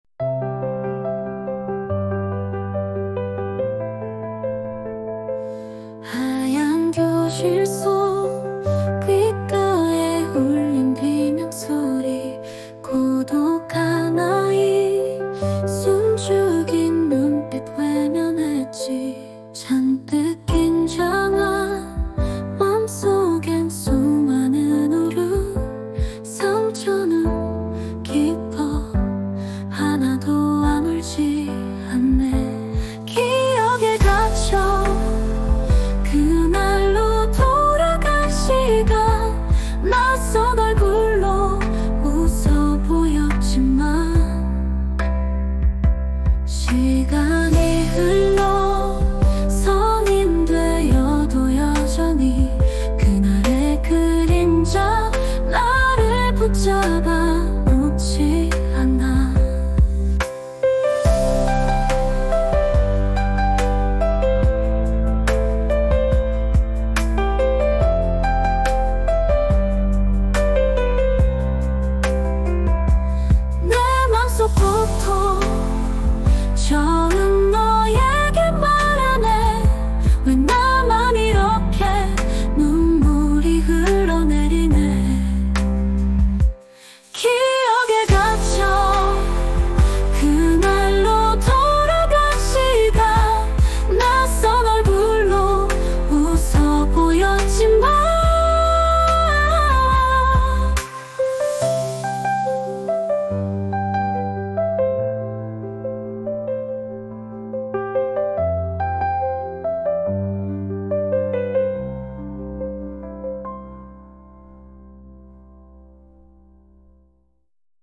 수노에 간단한 상황명령어 “학창 시절에 당한 학교 폭력으로 고통스러운 삶을 살아온 주인공이 나오는 드라마의 OST를 만들어줘”을 입력하니, 몇 분 만에 작사, 작곡, 가창, 앨범 커버까지 제작한다.